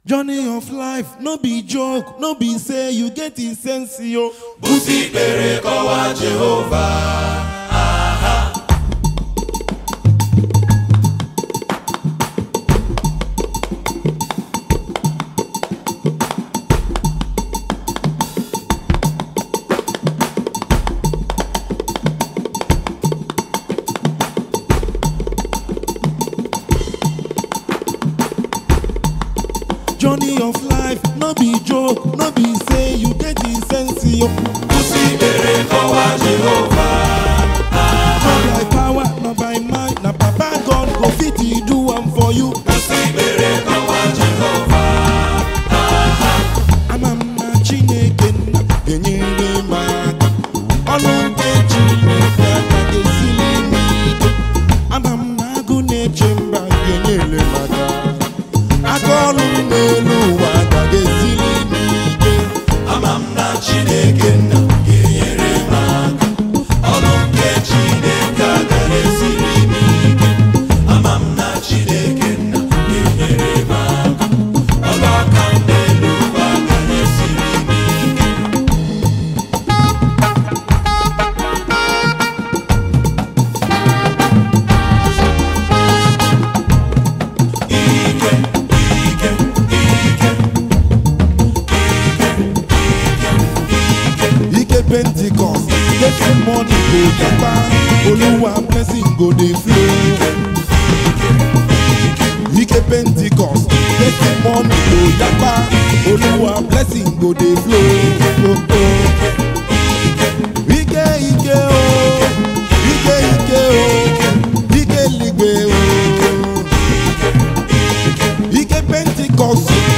February 8, 2025 Publisher 01 Gospel 0
songwriter and saxophonist.
heavily blended in traditional African rhythms